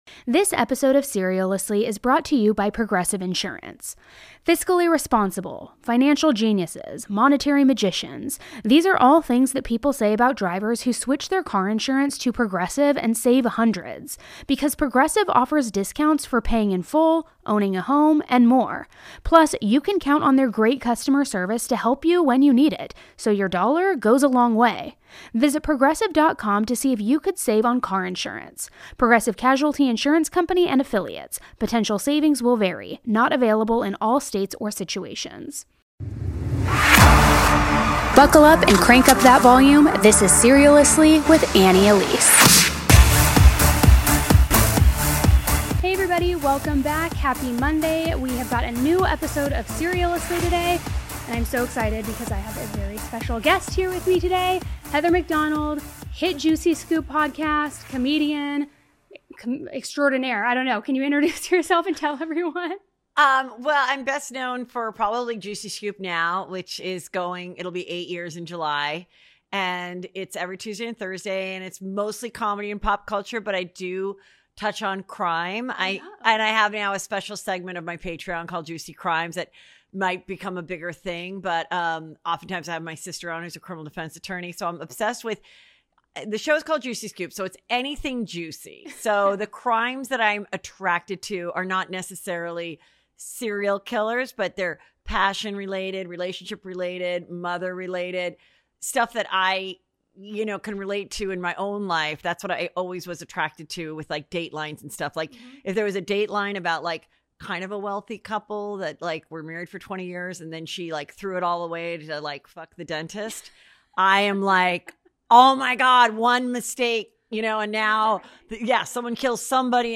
We'll be delving into the nitty-gritty of these cases, from the clues to the possible motives. So sit back, relax, and get ready for a gripping conversation with Heather McDonald on this week's episode of our true crime podcast.